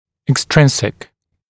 [ek’strɪnsɪk] [ɪk-][эк’стринсик] [ик-]внешний, наружный, неприсущий, несвойственный